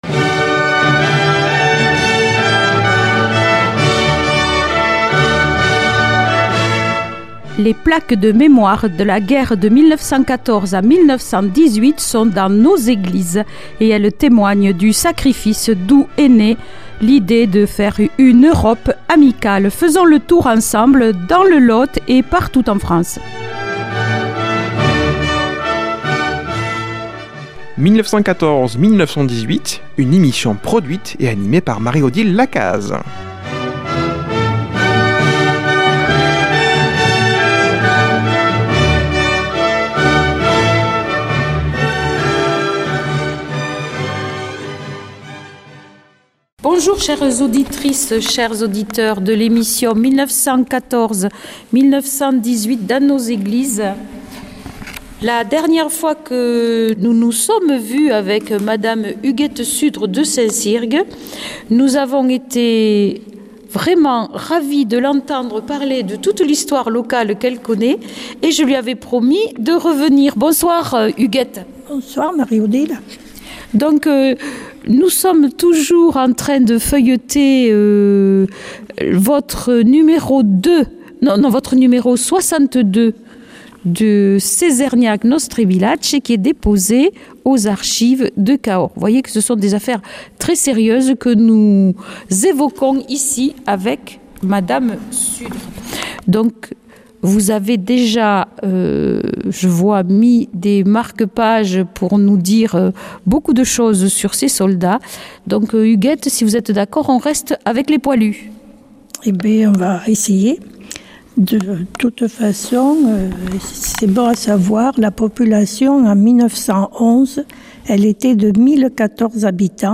à l’église de St Cirgues